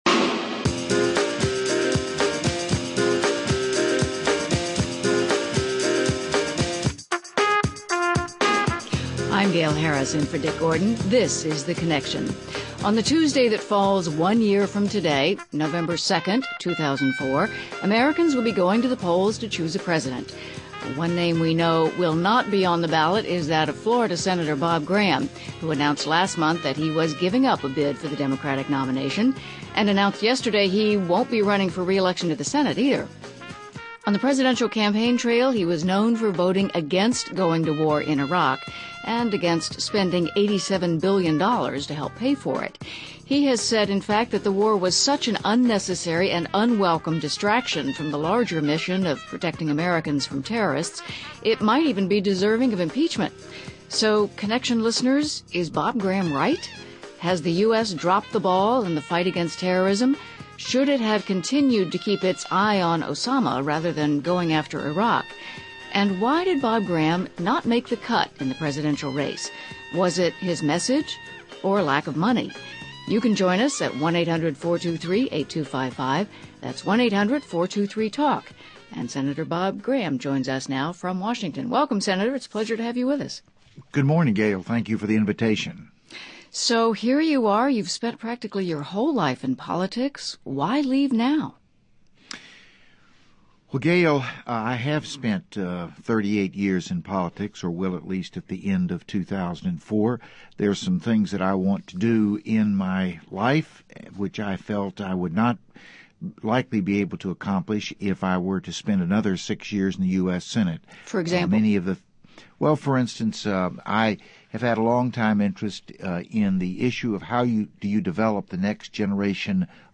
Guests: Senator Bob Graham, Democrat from Florida